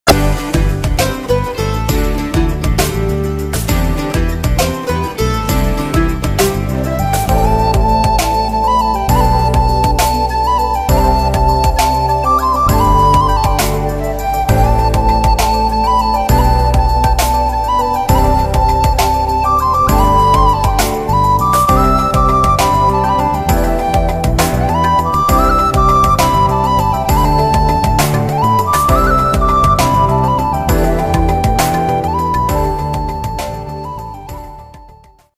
• Powerful Tamil folk-style music
• Mass & energetic beats